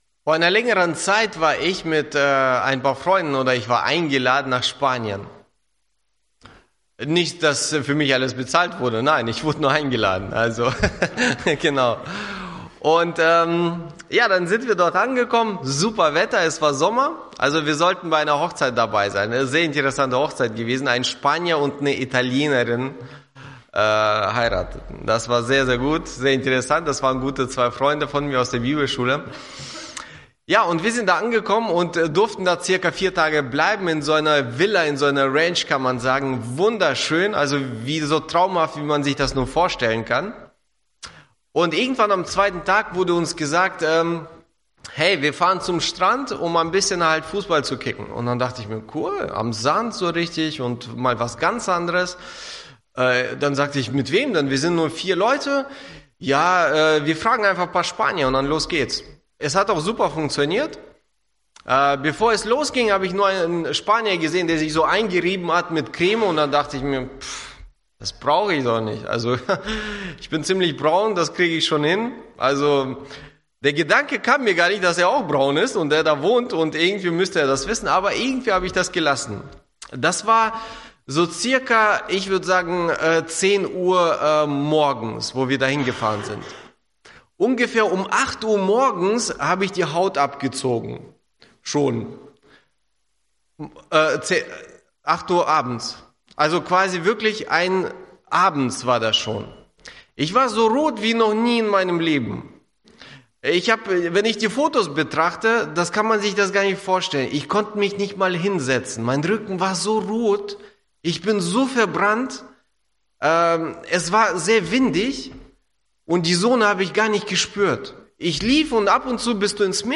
Verknüpfte Predigten